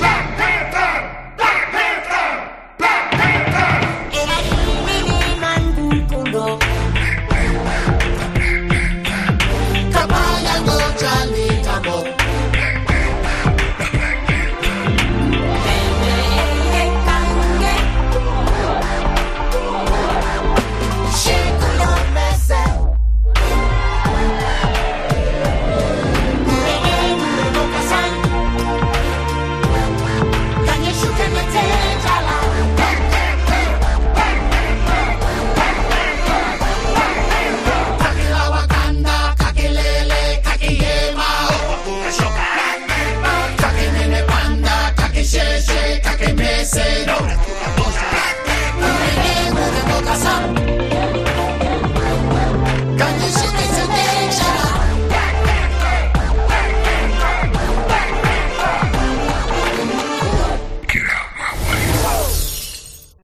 BPM86-86
Audio QualityCut From Video